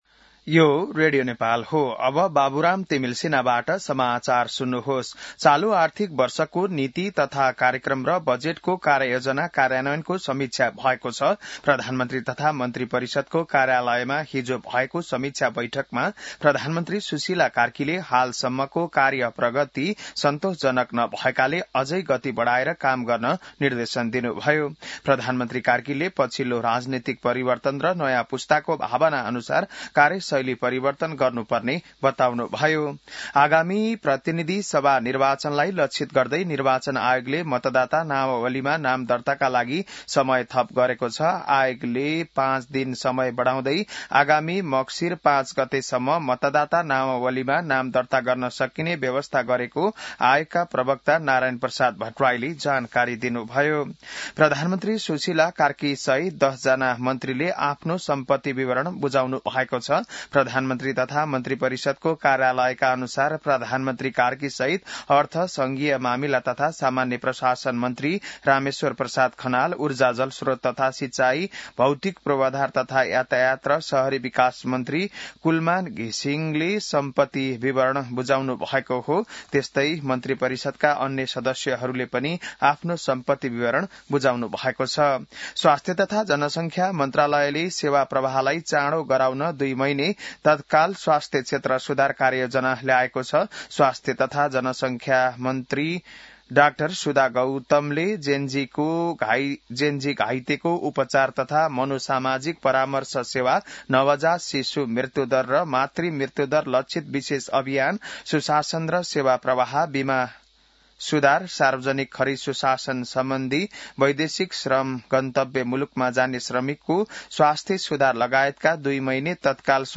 बिहान १० बजेको नेपाली समाचार : २९ कार्तिक , २०८२